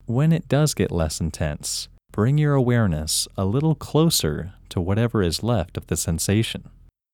IN – First Way – English Male 13
IN-1-English-Male-13.mp3